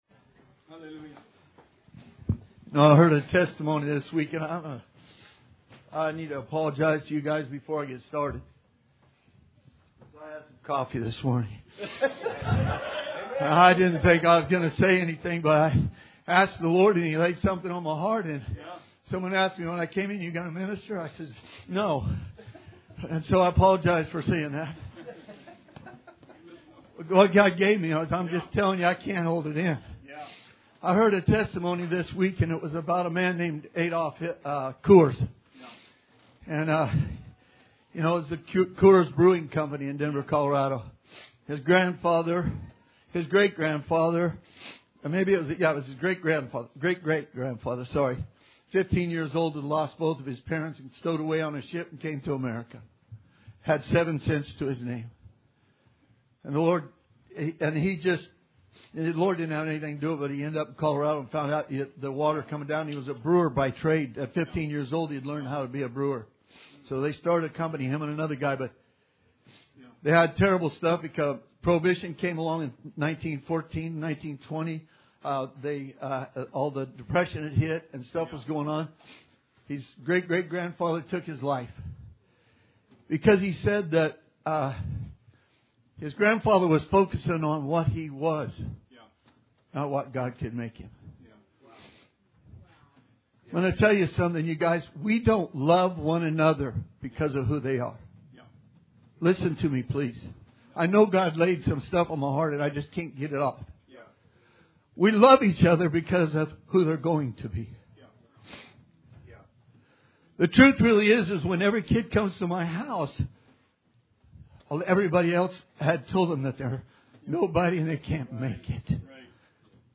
Sermon 1/12/20